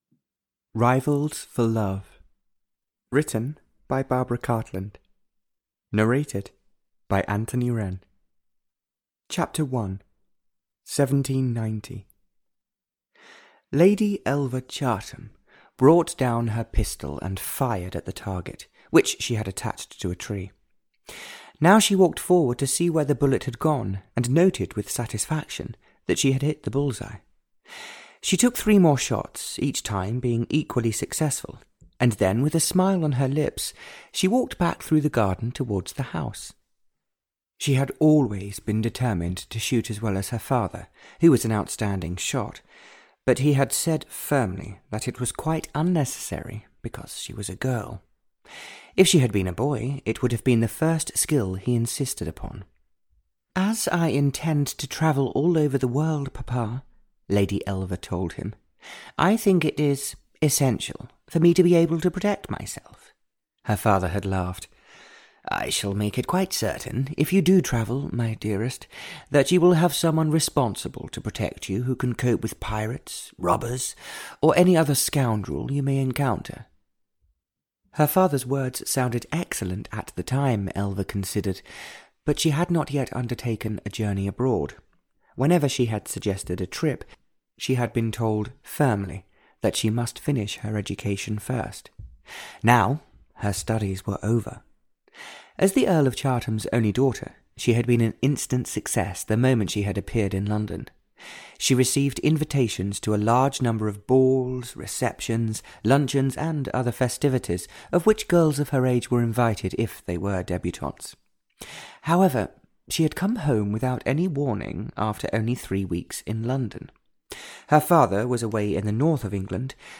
Rivals for Love (EN) audiokniha
Ukázka z knihy